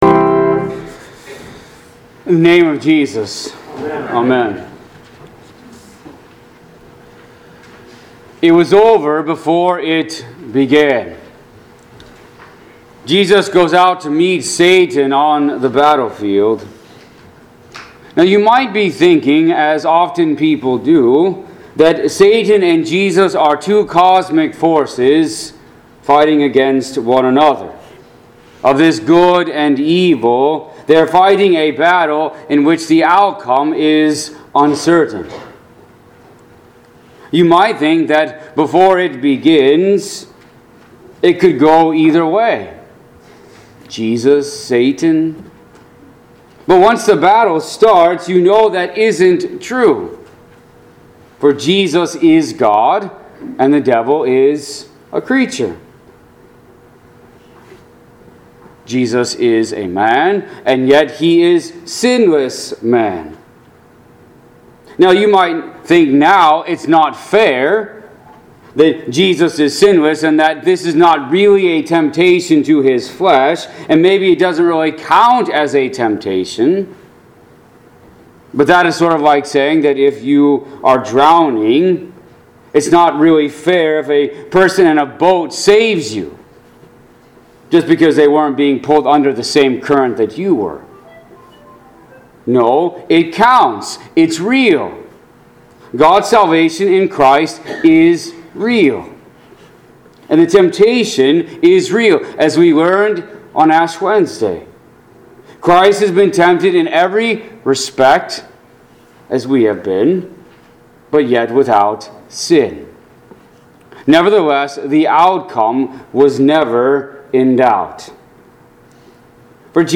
Pilgrim Ev. Lutheran Church - Audio Sermons